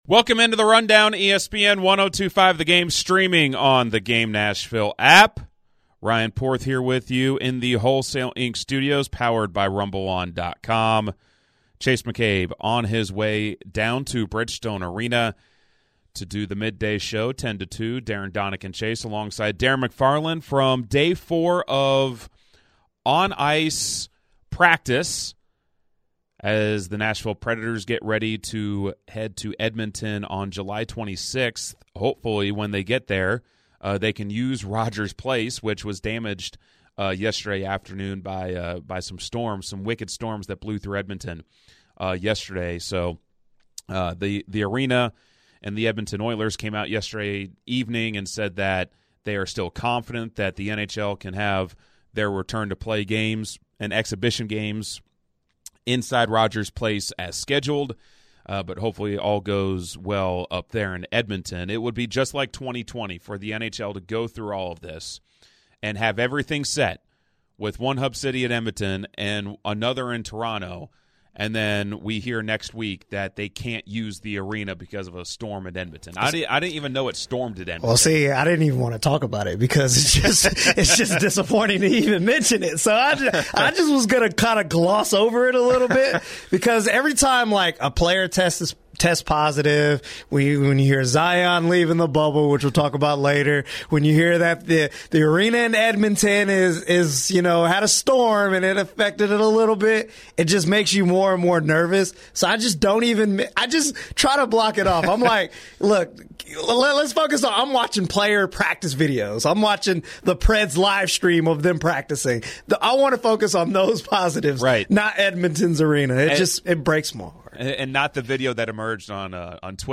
live from Bridgestone Arena